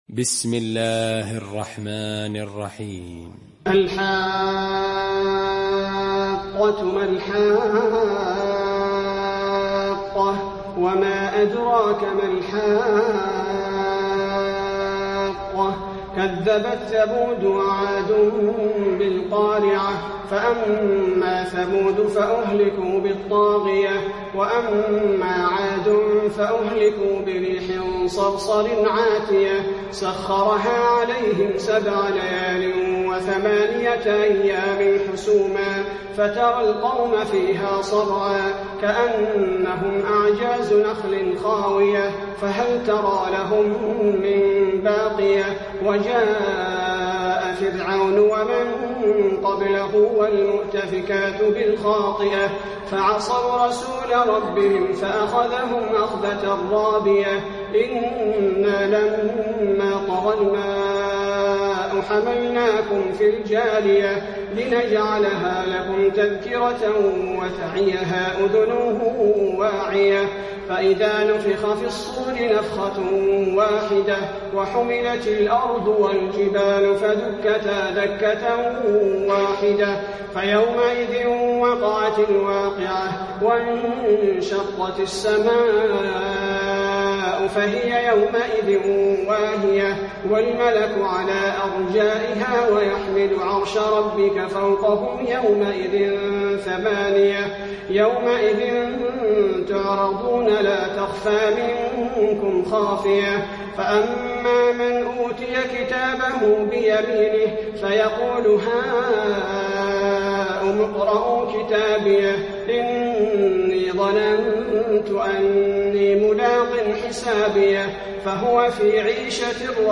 المكان: المسجد النبوي الحاقة